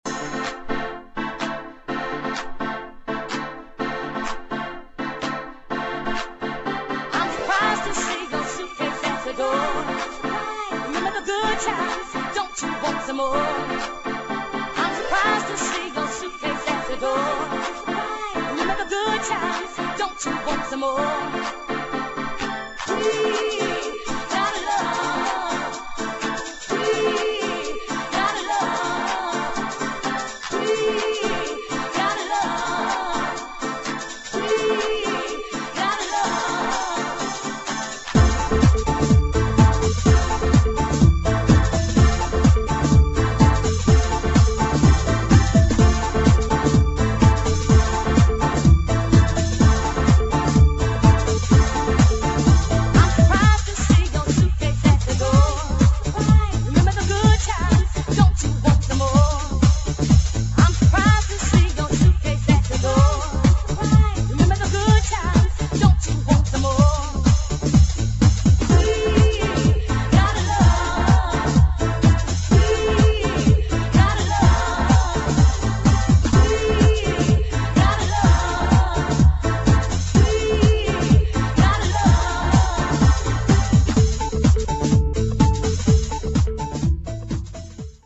[ HOUSE / GARAGE HOUSE ]